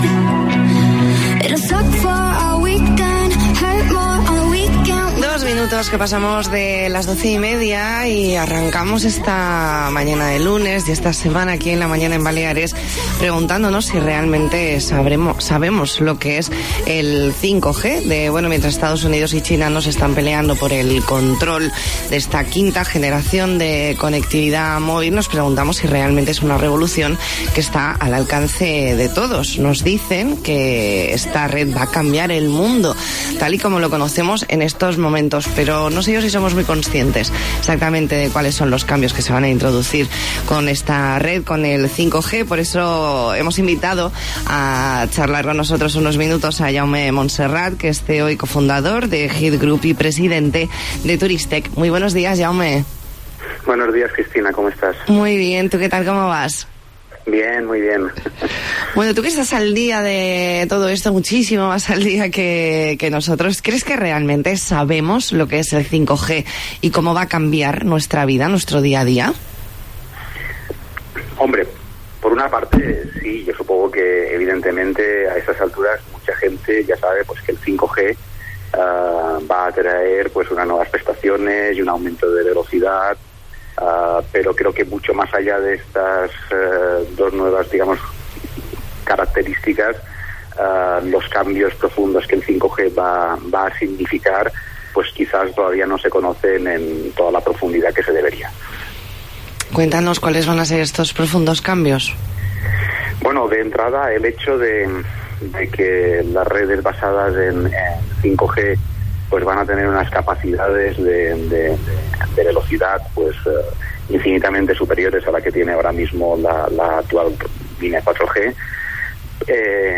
Entrevista en La Mañana en COPE Más Mallorca, lunes 13 de enero de 2020.